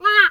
duck_2_quack_07.wav